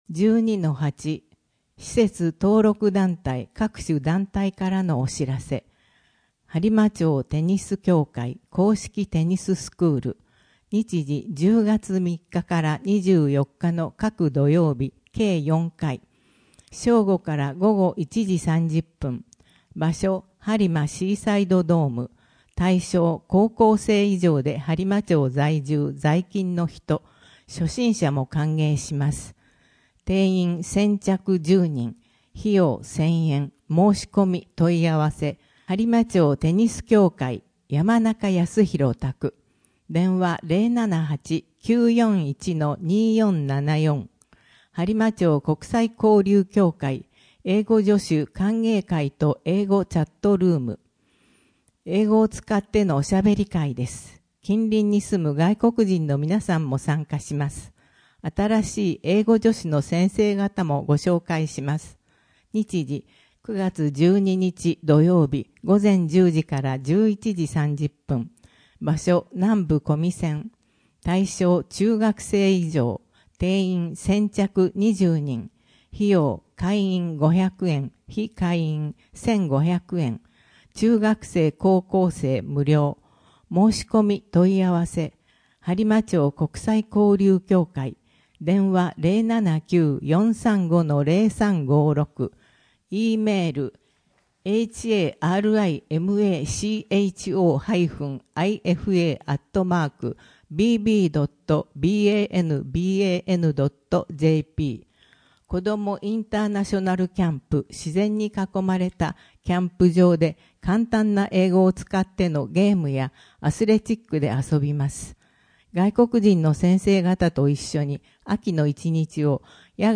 声の「広報はりま」9月号
声の「広報はりま」はボランティアグループ「のぎく」のご協力により作成されています。